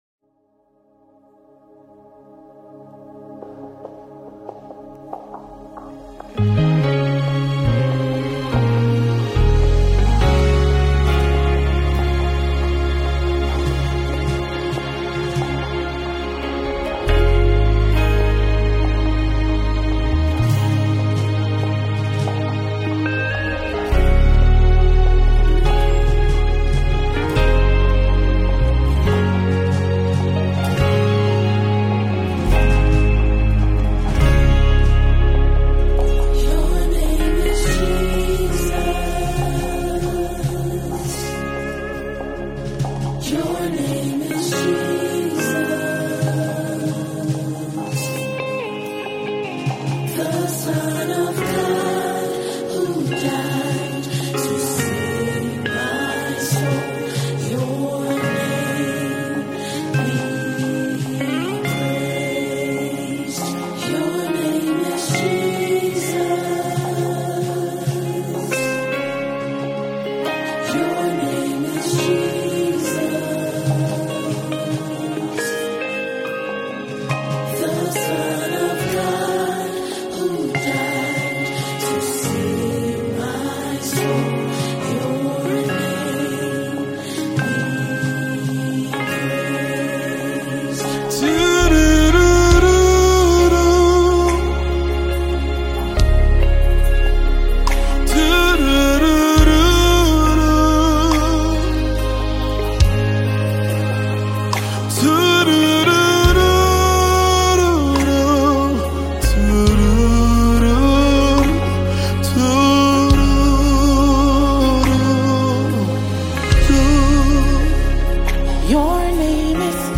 a heartfelt worship anthem.
What a powerful worship song!